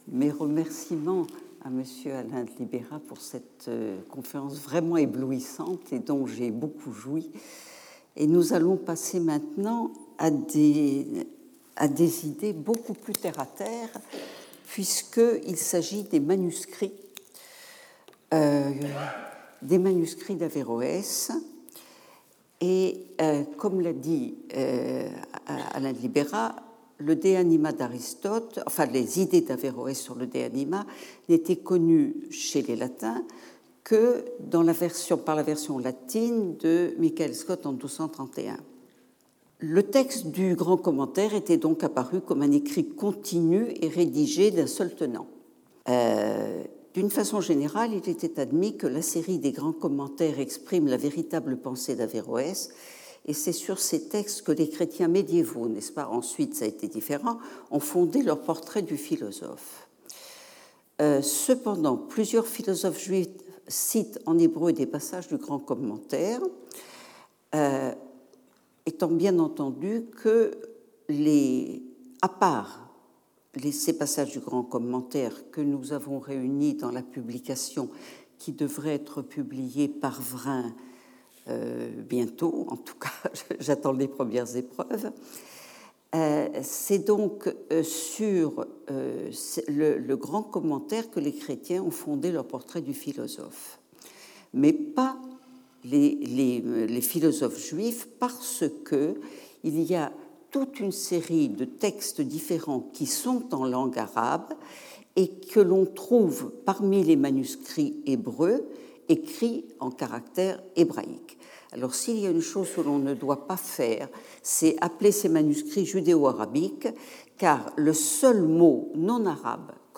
Seminar